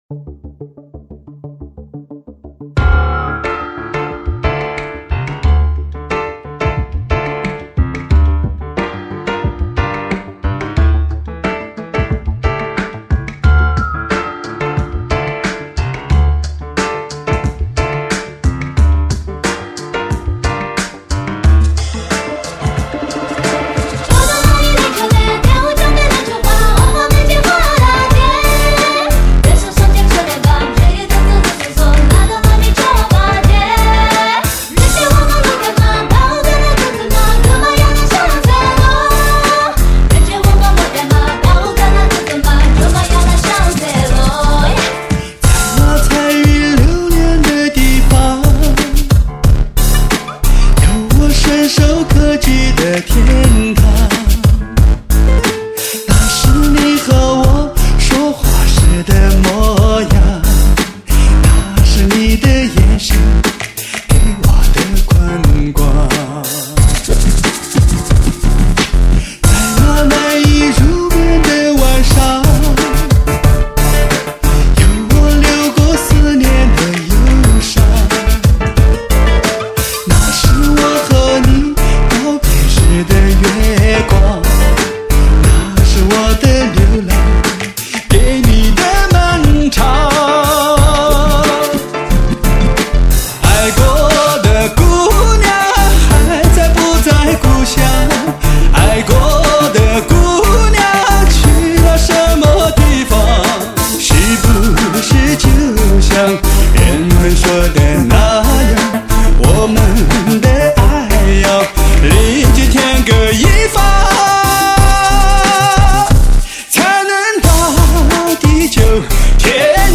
强劲电音 狂野节奏
专辑格式：DTS-CD-5.1声道
精挑细选欧洲最新颖的电音强劲的节奏，让您感受国外尖端大师的电频时代。